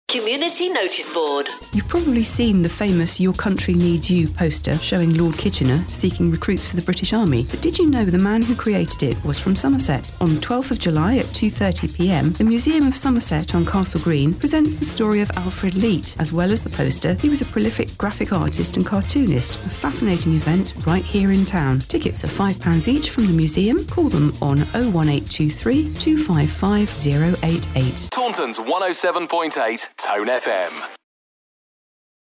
Catch some of her latest broadcasts here.